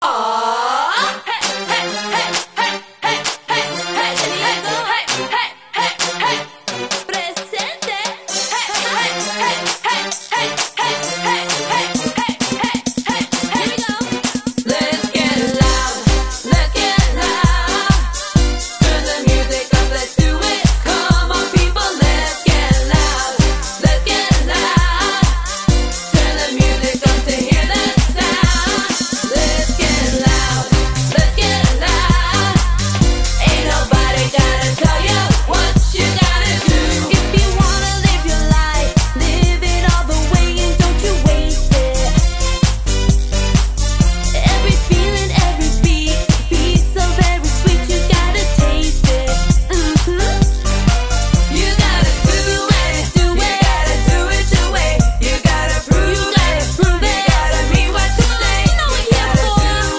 Neyland Stadium was the loudest it has ever been in its 101 years of existence vs Alabama. Vols fans shattered the previous stadium record of 118 decibels, reaching a volume of 125.4 during the Tennessee football 52-49 win over No. 1 Alabama - were you there?